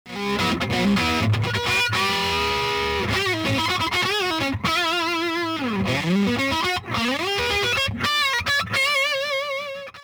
オーバードライブとしても使用できる超広域設計のフルレンジ・ブースター！ボリューム、ゲイン、ベース、トレブルのシンプルなコントロールにより、温かく上質なサウンドを表現。コード感を崩す事無く多彩なドライブコントロールが可能です。